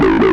tekTTE63012acid-A.wav